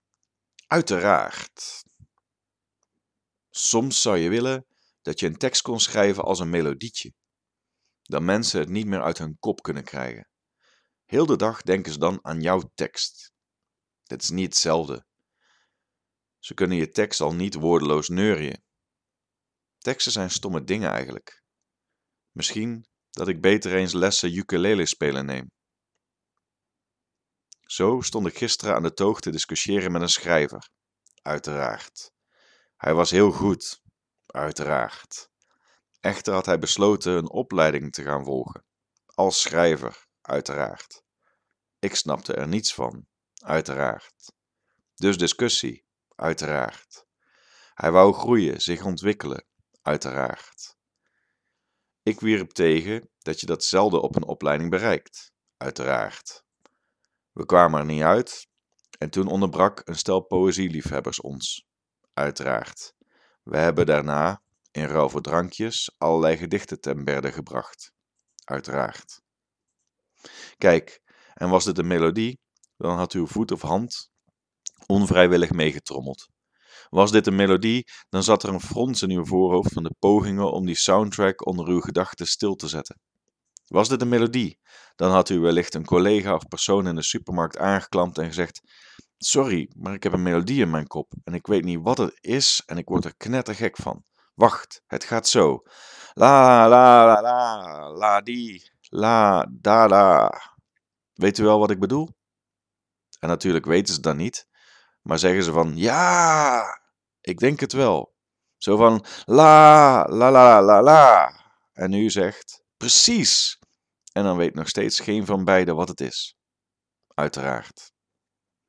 Audio stories Korte proza